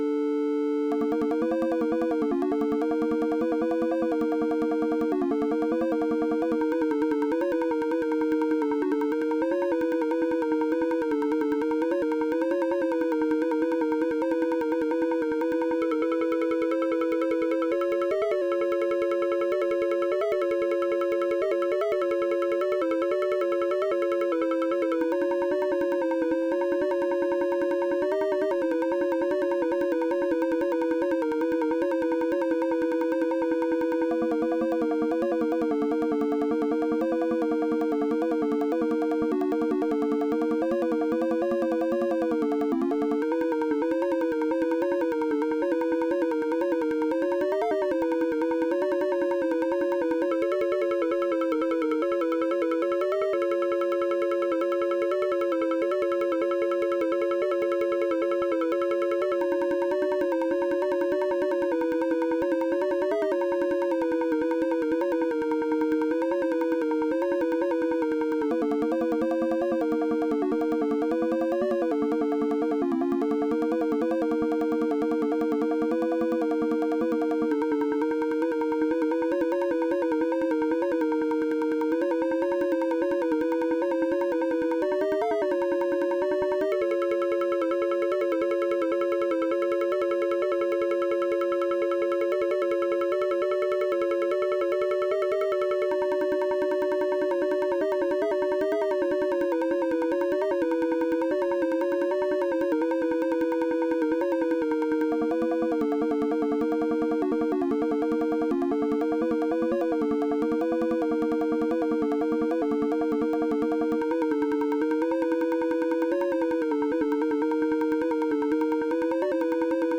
Data Sonification of the Average Daily Temperatures in San Francisco from 1 Jan 1995 to 10 Sept 2006